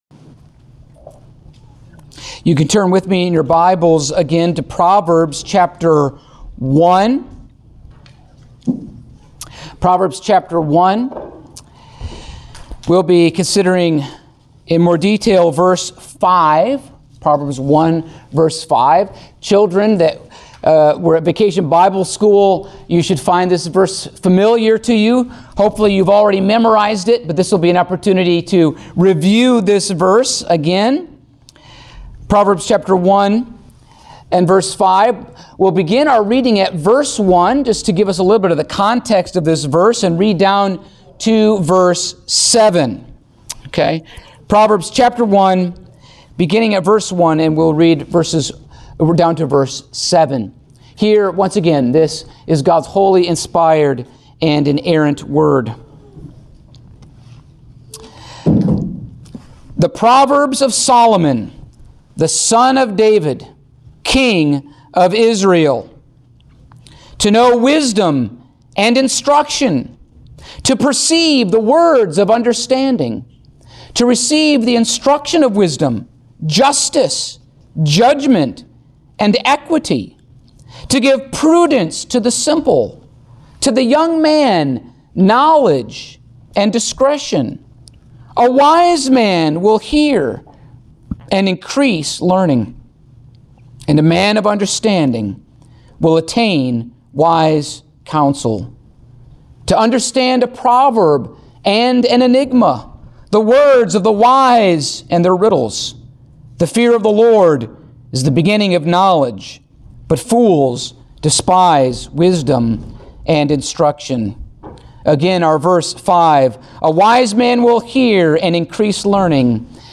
Passage: Proverbs 1:5 Service Type: Sunday Morning